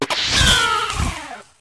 meowscarada_ambient.ogg